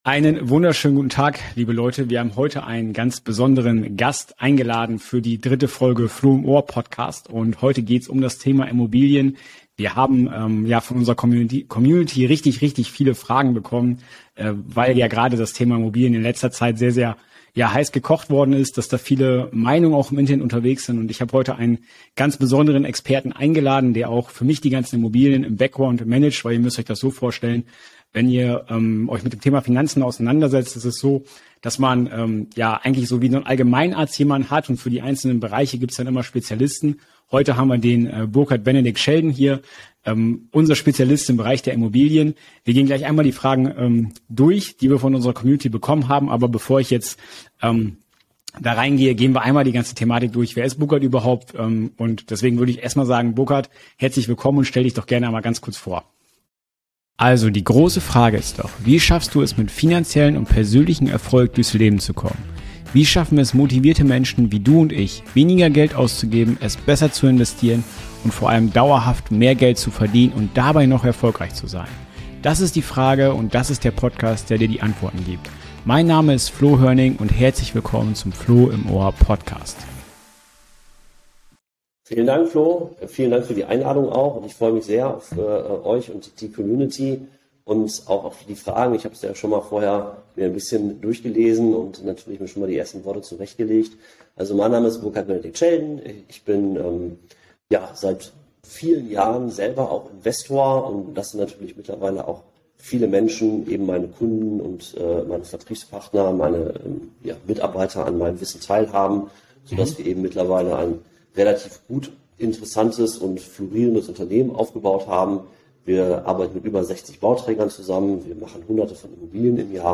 #3 Lohnen sich 2022/2023 noch Immobilien? Experteninterview ~ CashFlo Podcast